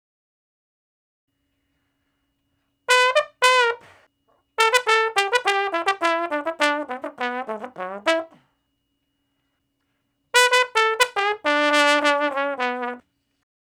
092 Bone Slo Blus 02 (E IV, IV, I, I).wav